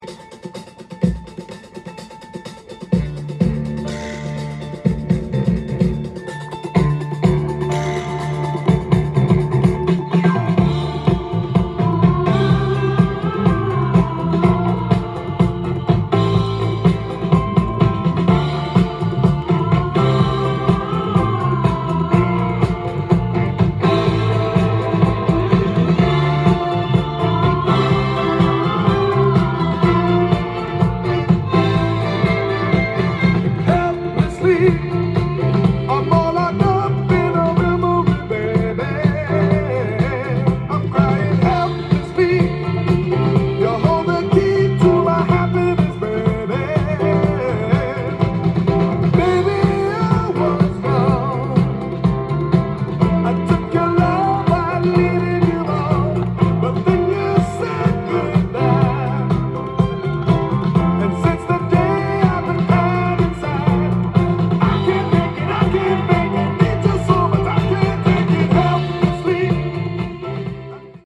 ジャンル：Soul-7inch-全商品250円
店頭で録音した音源の為、多少の外部音や音質の悪さはございますが、サンプルとしてご視聴ください。
音が稀にチリ・プツ出る程度